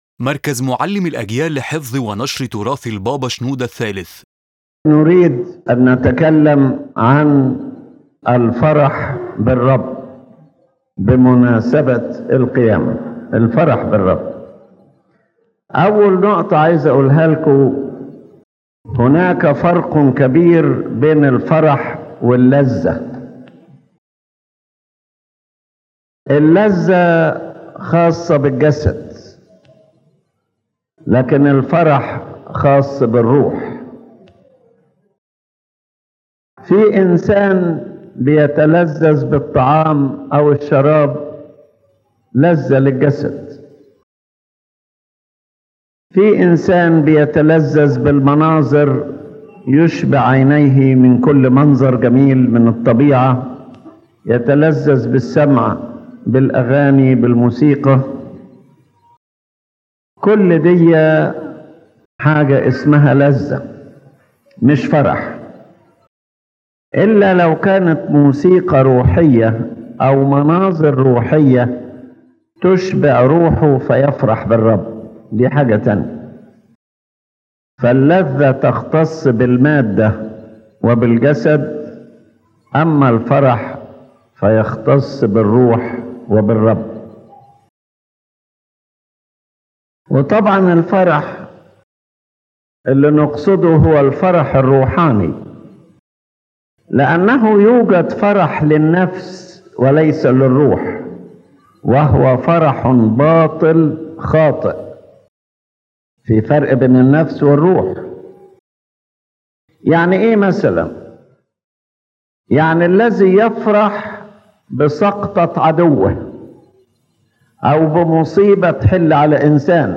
Main chapters of the lecture The difference between pleasure and joy: pleasure is related to the body and the senses, while joy is nourishment for the spirit and has spiritual roots.